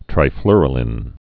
(trī-flrə-lĭn)